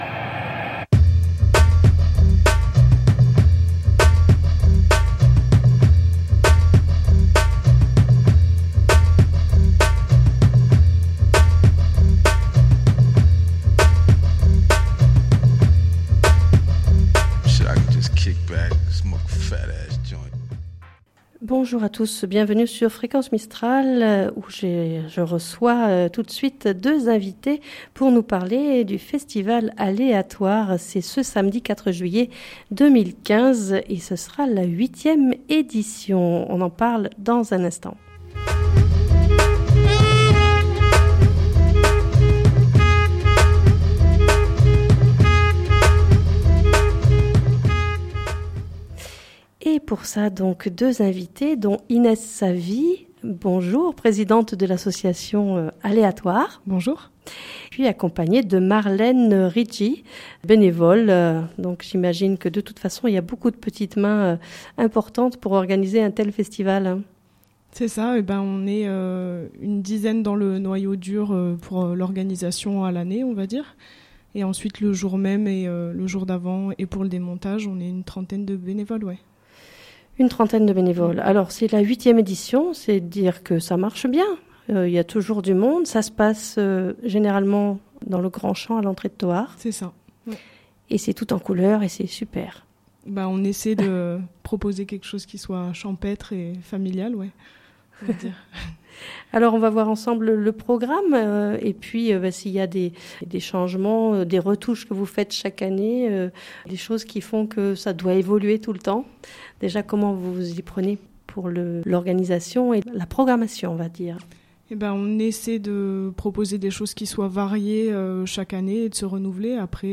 avec des extraits de musique des différents groupes participants au Festival du cru 2015.